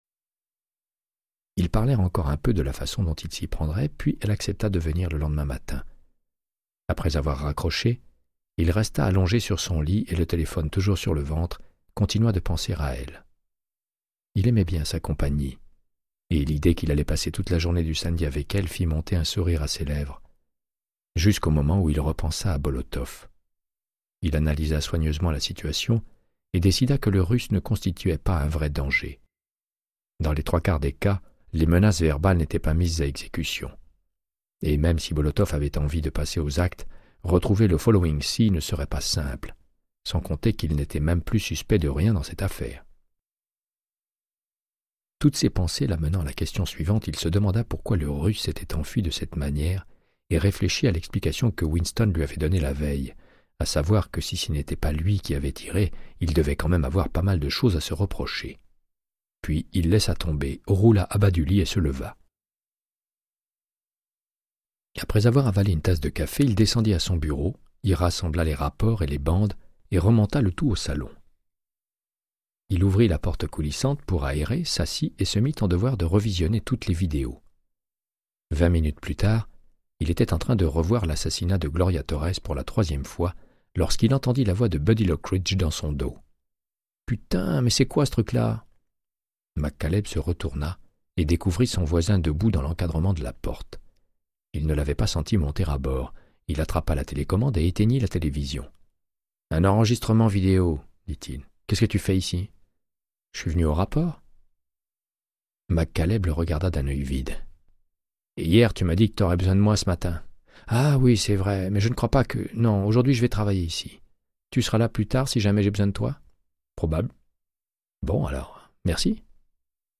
Audiobook = Créance de sang, de Michael Connellly - 74